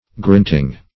grinting - definition of grinting - synonyms, pronunciation, spelling from Free Dictionary Search Result for " grinting" : The Collaborative International Dictionary of English v.0.48: Grinting \Grint"ing\, n. Grinding.